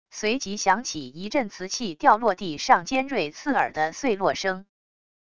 随即响起一阵瓷器掉落地上尖锐刺耳的碎落声wav音频